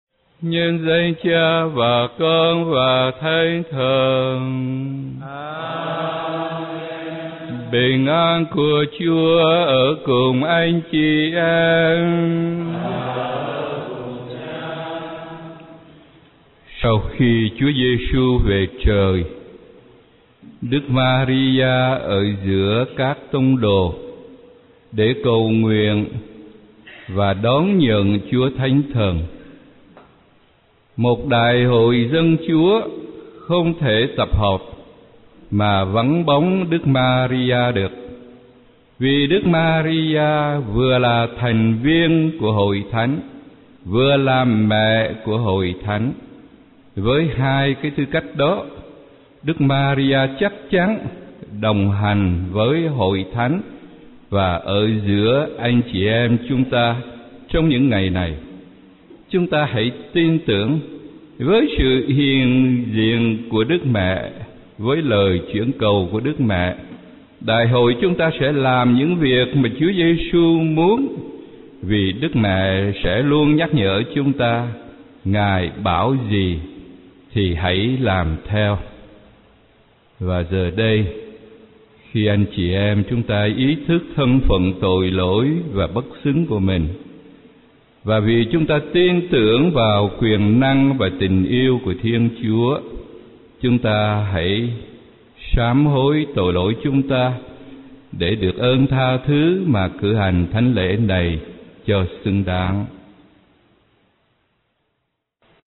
Nhập Lễ sáng 22/11/2010